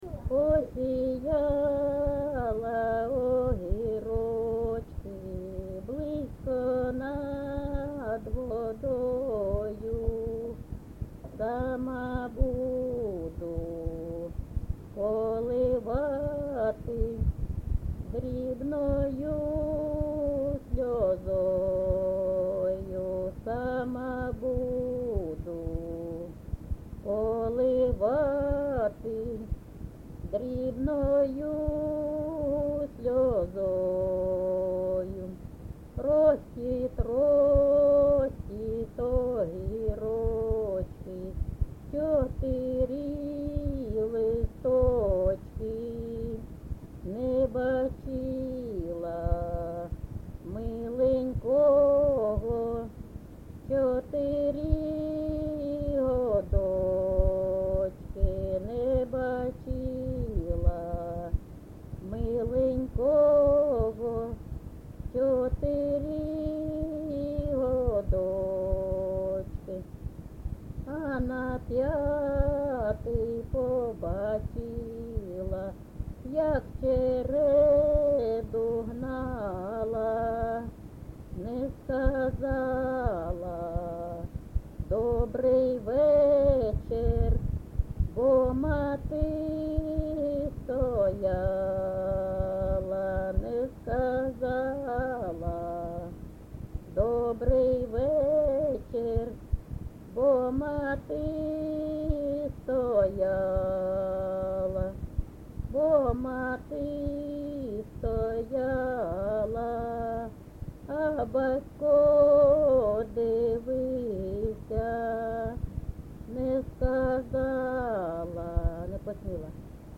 ЖанрПісні з особистого та родинного життя
Місце записус. Хрестівка, Горлівський район, Донецька обл., Україна, Слобожанщина